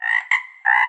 animalia_frog_1.ogg